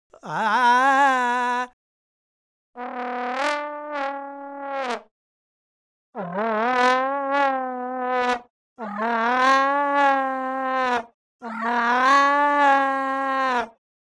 Listen to this example of a male singer morphed with a trombone, played using a technique called "fluttertoungue". The singer and the trombone are presented individually, followed by a sequence of morphs.
All sound morphs and syntheses presented here were created using the open source Loris software for sound analysis, synthesis, and manipulation.